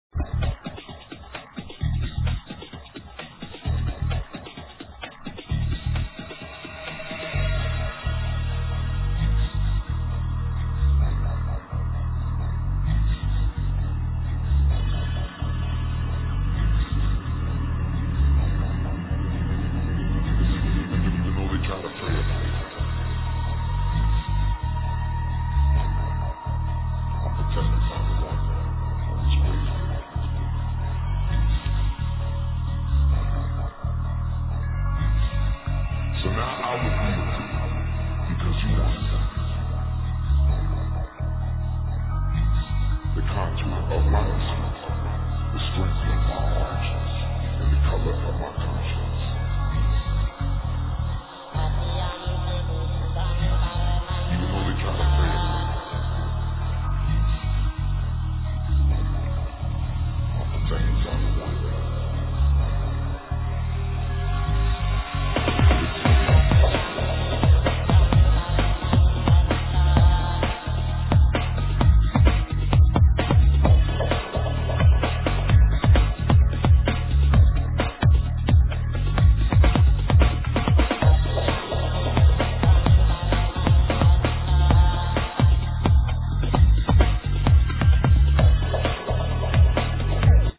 sweet breaks tune